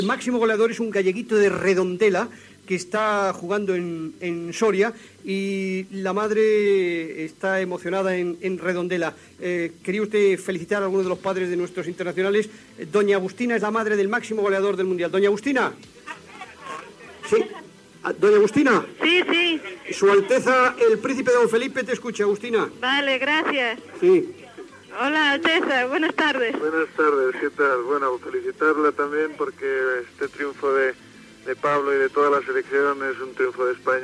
Fragment d'una conversa entre la mare d'un jugador de futbol i el príncep Felipe de Borbón
Esportiu